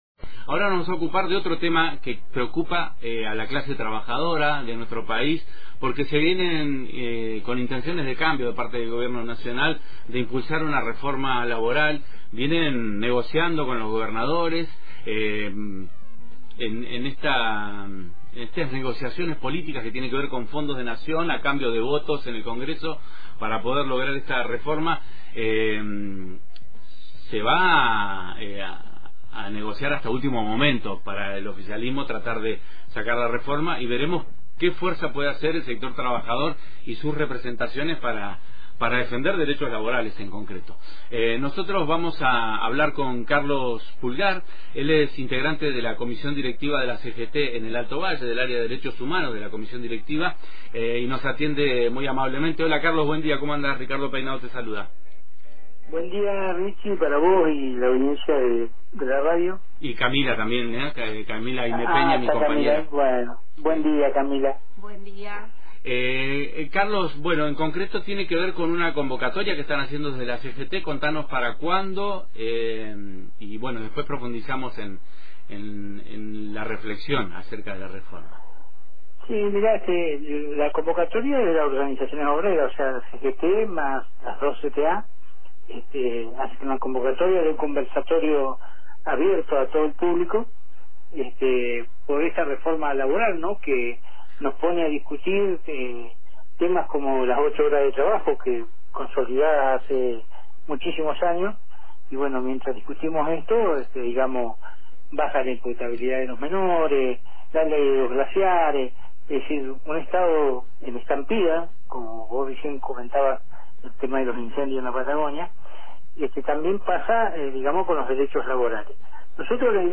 La convocatoria tiene como objetivo debatir las políticas económicas del gobierno nacional, y el tratamiento de la reforma laboral. Escuchá la entrevista completa desde acá